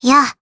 Gremory-Vox_Jump_jp_b.wav